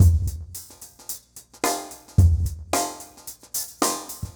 RemixedDrums_110BPM_26.wav